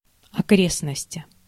Ääntäminen
US : IPA : [ˈneɪ.bɚ.ˌhʊd]